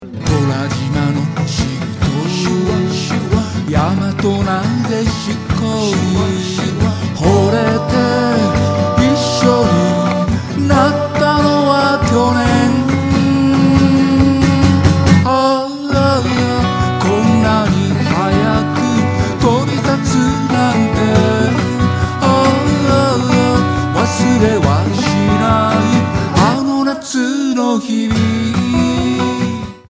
Percussion / Cajon / Cho.
Vo. / A.Guitar
A.Guitar / E.Guitar / Cho.